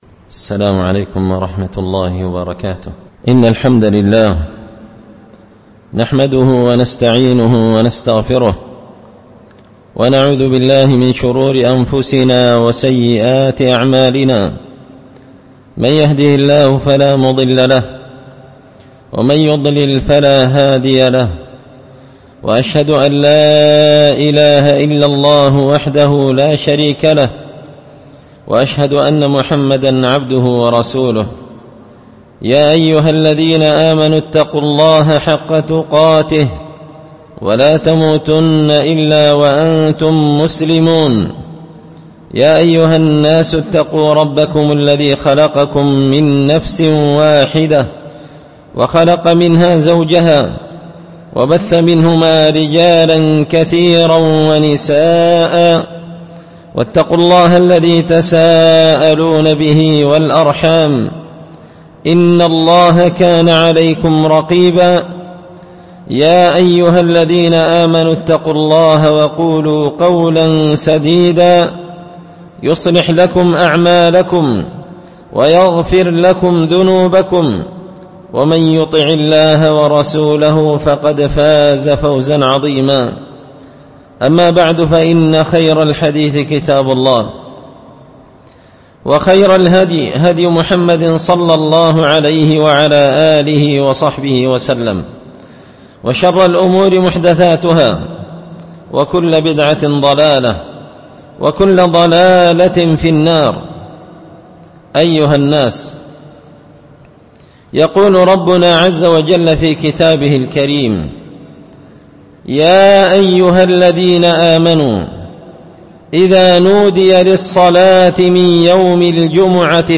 خطبة جمعة بعنوان _الجمعة آداب أحكام _17 ربيع الثاني 1444هـ
الجمعة 17 ربيع الثاني 1444 هــــ | الخطب والمحاضرات والكلمات | شارك بتعليقك | 29 المشاهدات